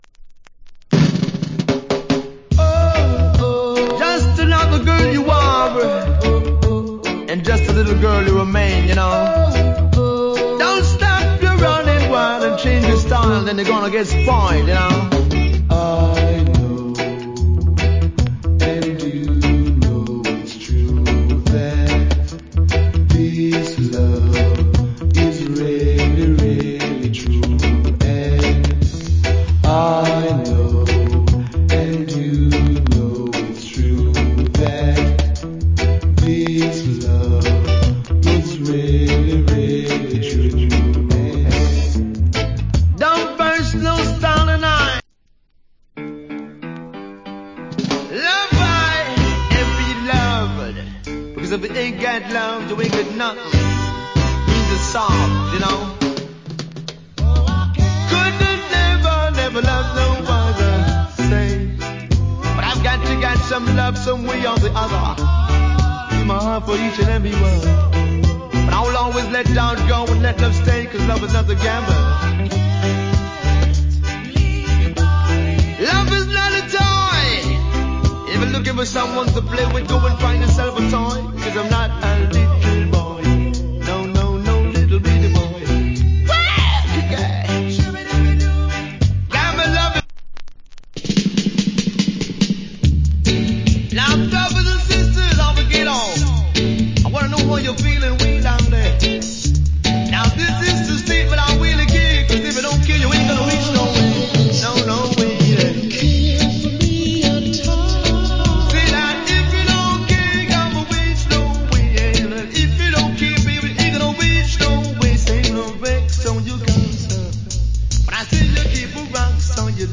REGGAE ROOTS ROCK
Nice DJ Roots Rock To Reggae Compilation Album.
VG(OK) *表面上に薄い擦り傷あり。 それによるチリノイズあり。